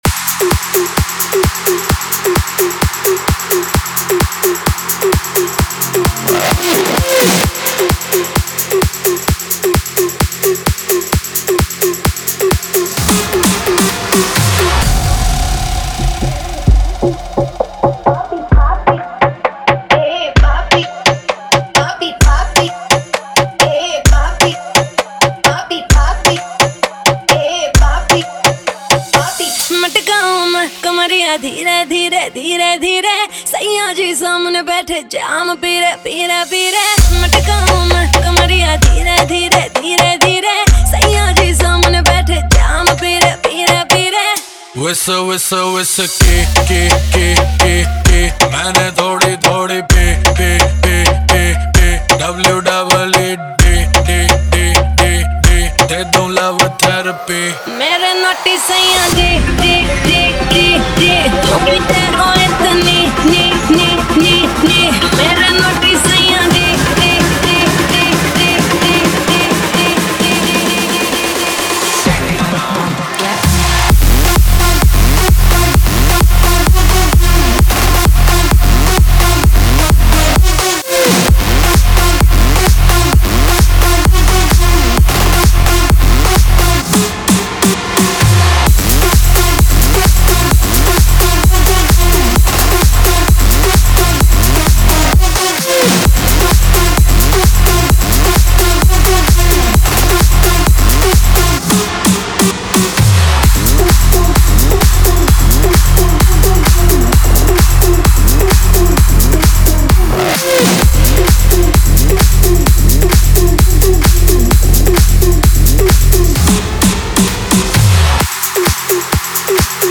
EDM Remix | Dance Remix Song
Dholki Remix Mp3 Song Free
Category: Latest Dj Remix Song